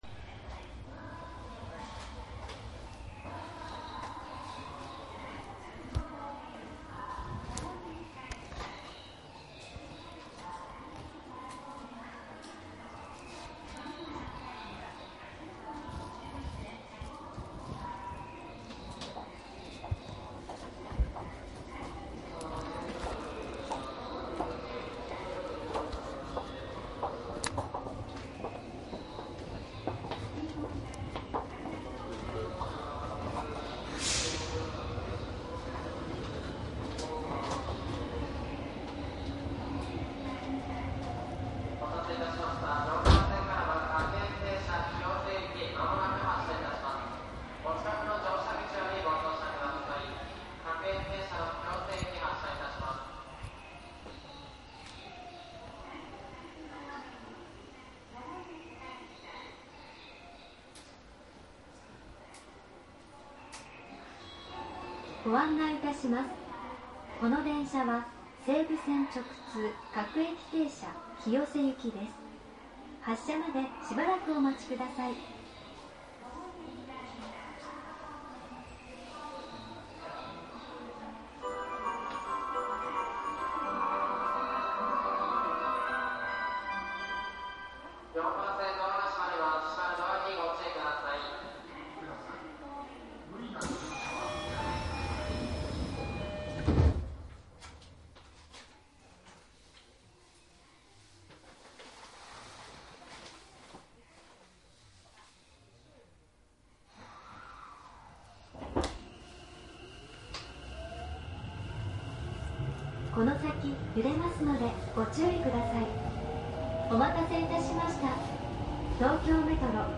東京メトロ副都心線10000系【各停】+西武線内 走行音CD
副都心線渋谷暫定開業時の録音になります。
マイクECM959です。MZRH1の通常SPモードで録音。
実際に乗客が居る車内で録音しています。貸切ではありませんので乗客の会話やが全くないわけではありません。